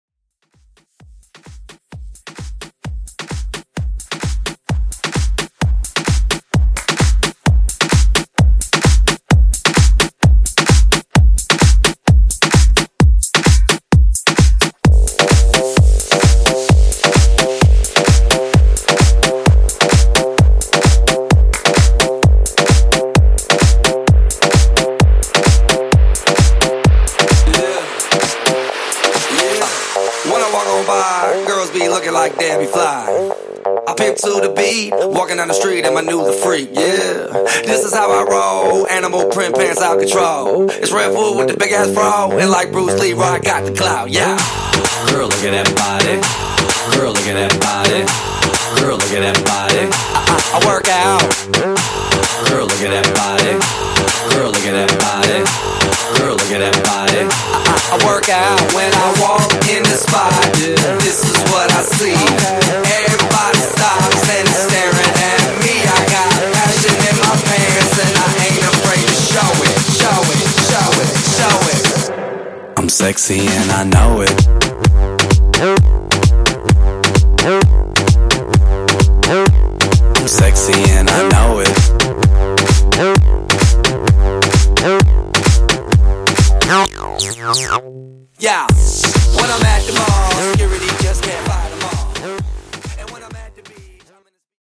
Genres: EDM , RE-DRUM
Clean BPM: 125 Time